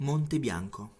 1. ^ French: Mont Blanc [mɔ̃ blɑ̃] ; Italian: Monte Bianco [ˈmonte ˈbjaŋko]
It-Monte_Bianco.ogg.mp3